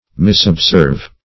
Search Result for " misobserve" : The Collaborative International Dictionary of English v.0.48: Misobserve \Mis`ob*serve"\, v. t. To observe inaccurately; to mistake in observing.